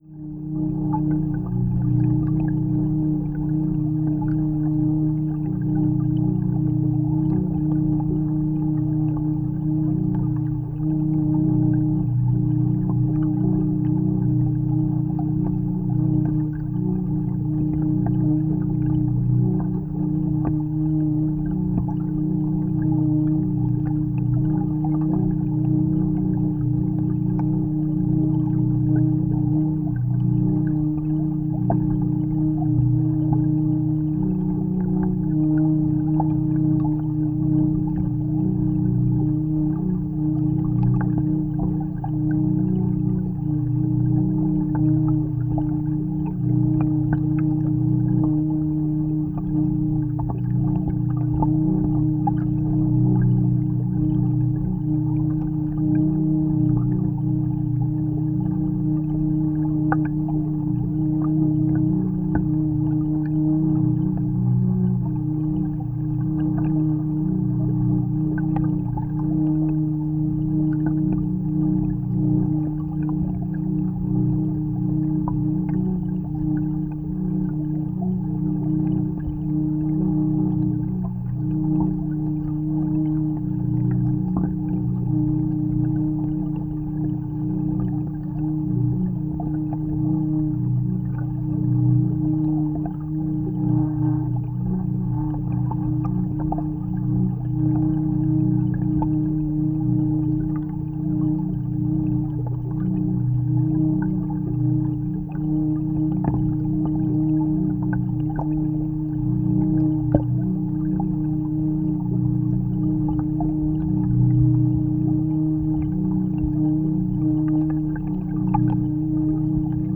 Strings that resonate with the chaotic waterstreams might provide interesting sounds and time-based variations.
A natural water stream is per definition unstable, so we will get only chaotic oscillations occuring.
waterstringdawn:waterstringstick-sample.wav